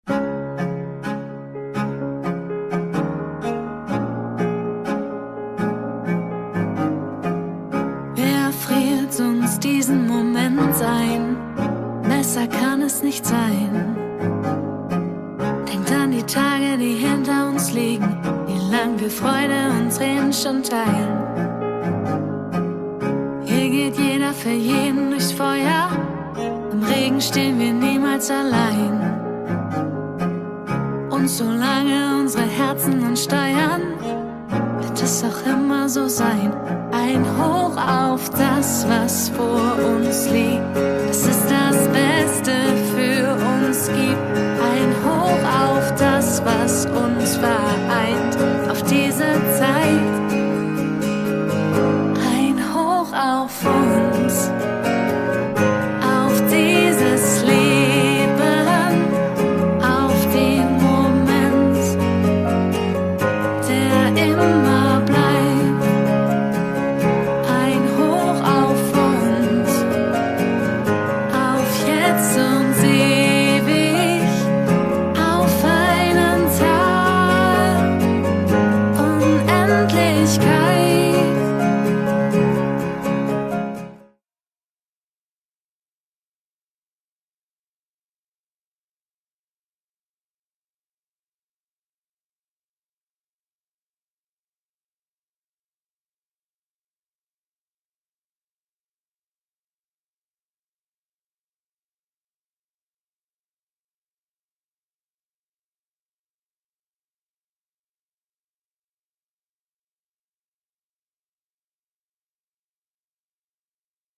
Gefühlvolle Balladen und mehr